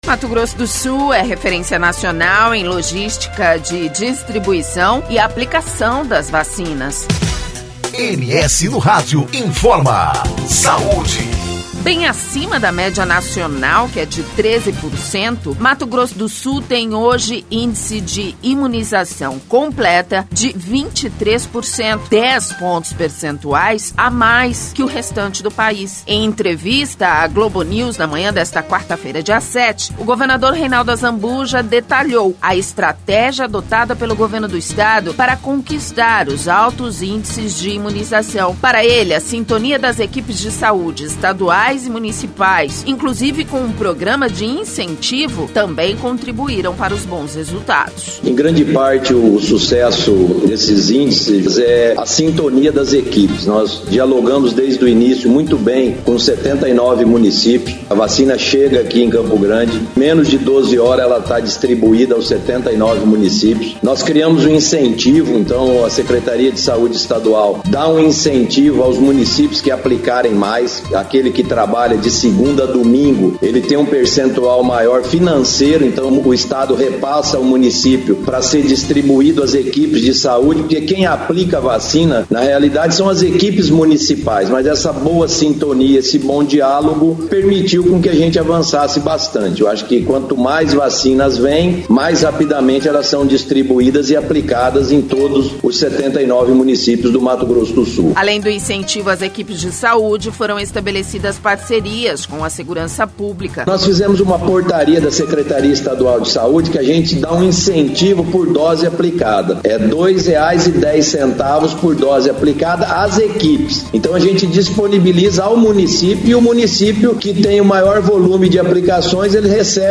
Em entrevista à Globonews, na manhã desta quarta-feira, dia 07, o governador Reinaldo Azambuja detalhou a estratégia adotada pelo Governo do Estado para conquistar os altos índices de imunização.